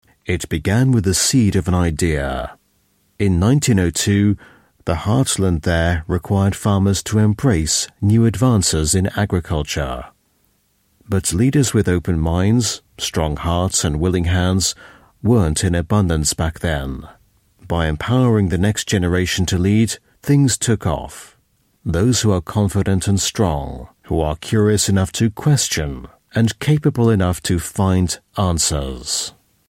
Dokus
Native Speaker